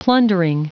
Prononciation du mot plundering en anglais (fichier audio)
Prononciation du mot : plundering